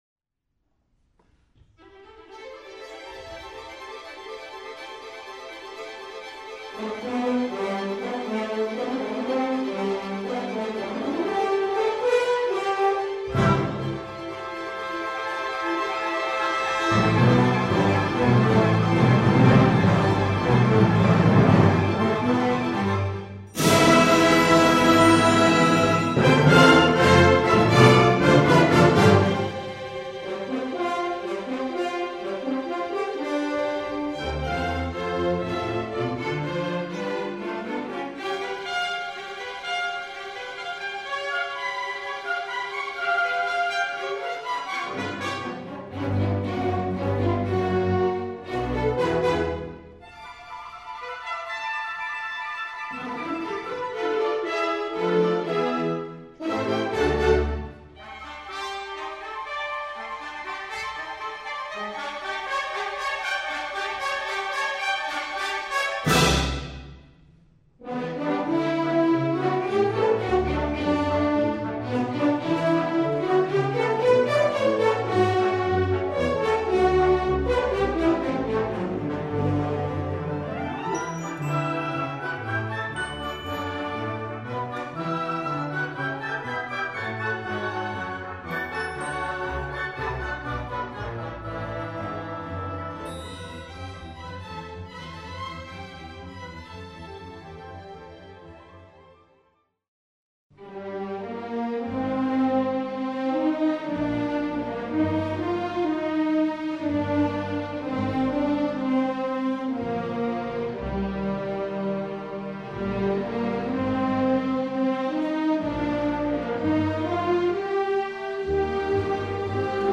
Suite for Large Orchestra
The elaborate score of The Planets produces unusual, complex sounds by using some unique instruments and multiples of instruments in the large orchestra (like Mahler's Sixth of 1906), such as three oboes, three bassoons, two piccolos, two harps, bass oboe, two timpani players, glockenspiel, celesta, xylophone, tubular bells, and organ (see "Instrumentation" below).